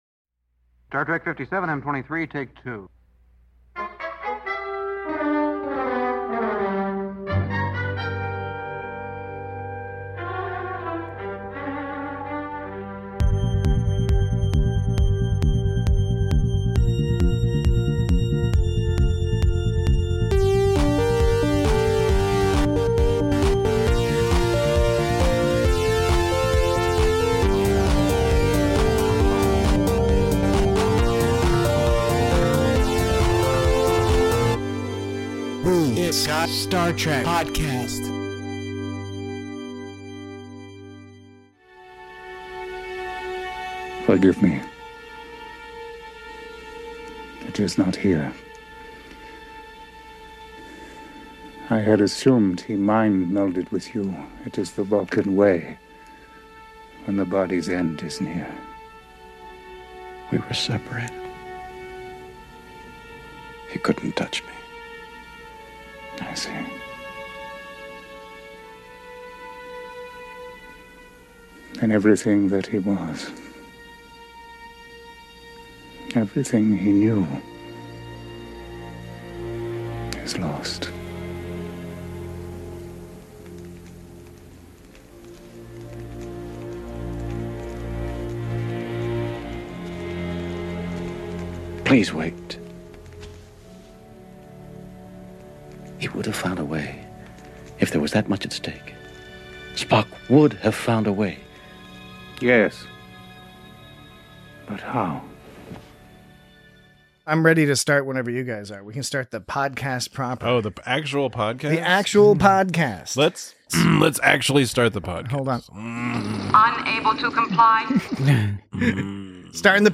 Note that this and all episodes of the It’s Got Star Trek podcast contain explicit language and, fran